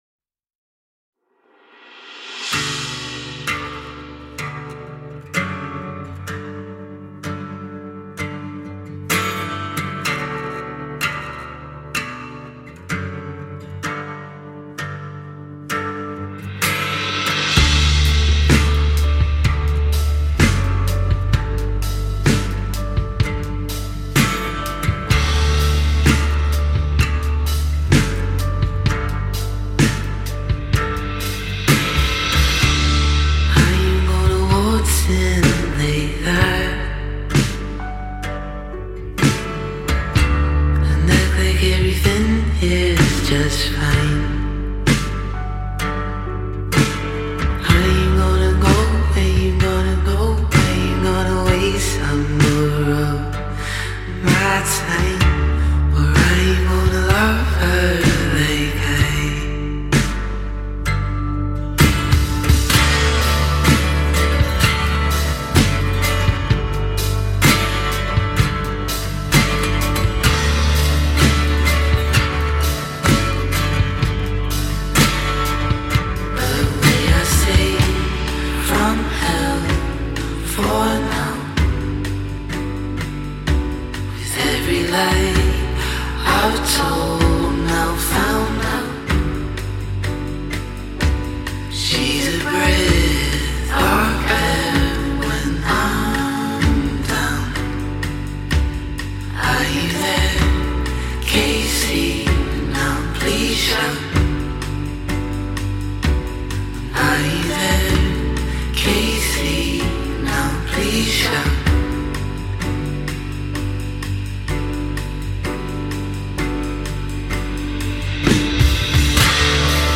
singer-songwriter
delicate vocals